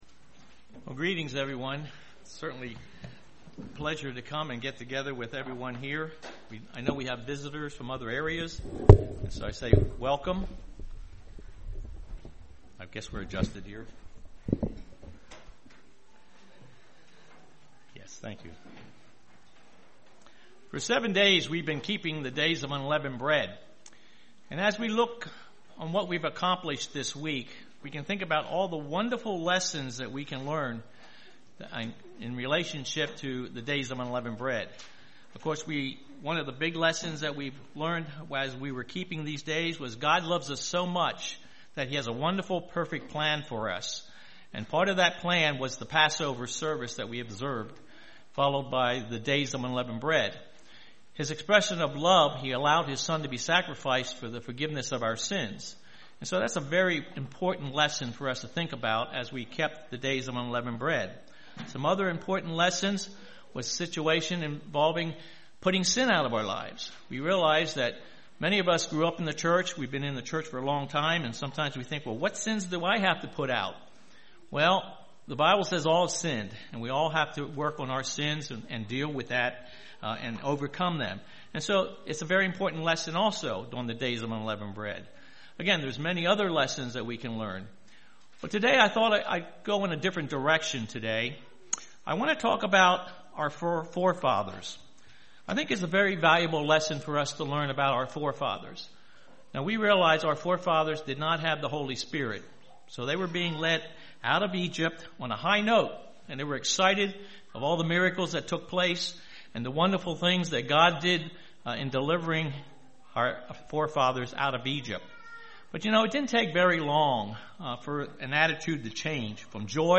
Listen to Sermon Is God's Law on Your Heart?